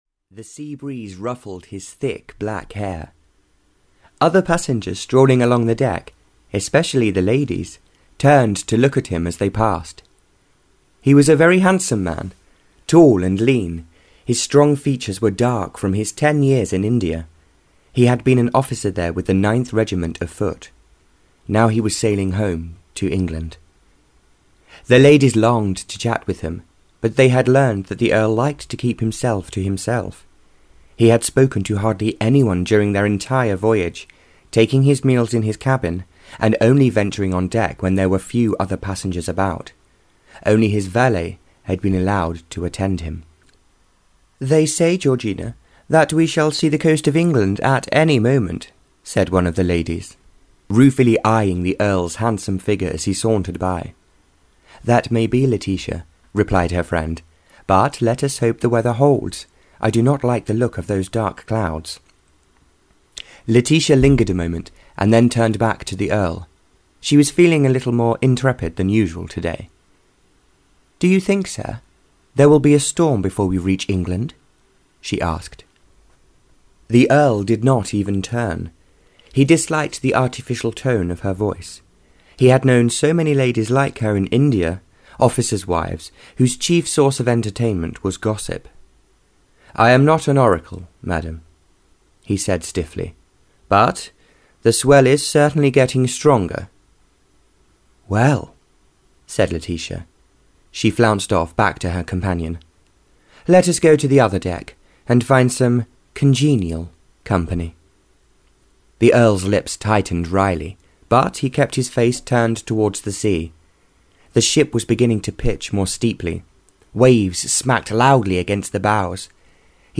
Audio knihaThe Castle of Love (Barbara Cartland’s Pink Collection 4) (EN)
Ukázka z knihy